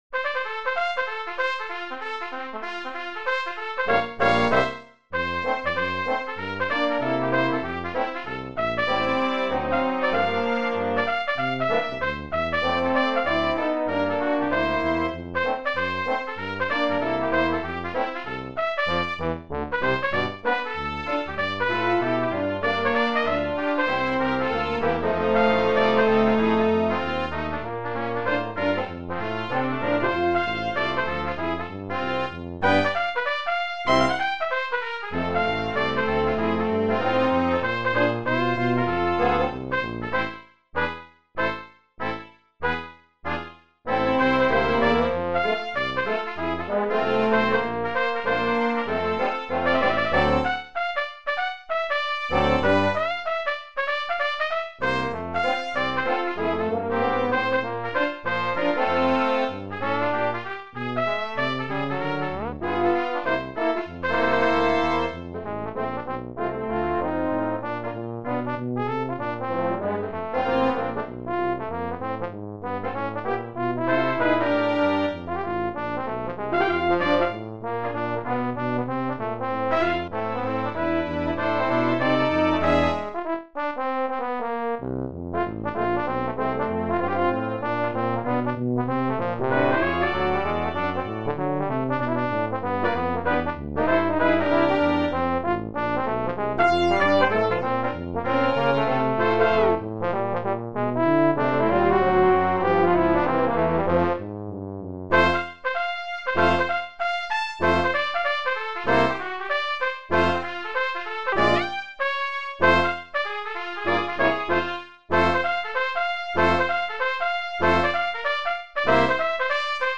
Brass Quintet
New Orleans jazz standards
Trombone has a written solo for the second chorus